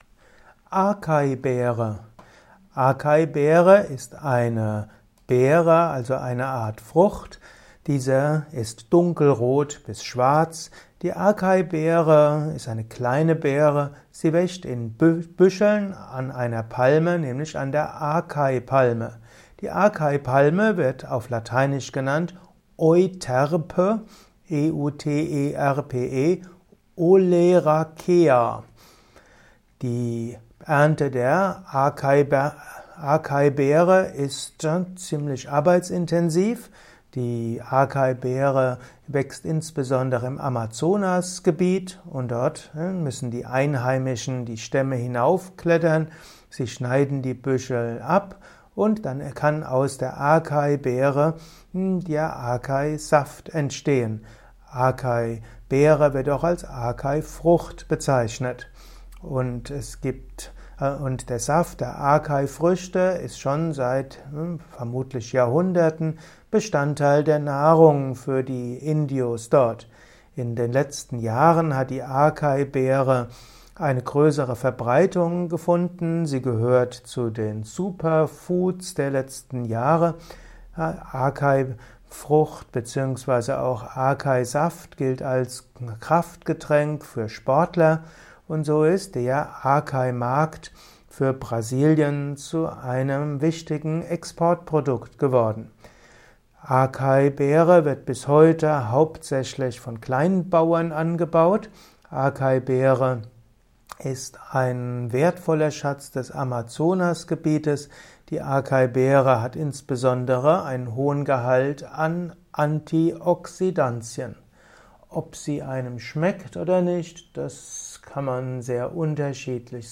Acai-Beere - Erfahre mehr über die Acai Beere in diesem Kurzvortrag. Höre etwas über Acai-Beere vom einem Standpunkt von Yoga und Yogatherapie aus.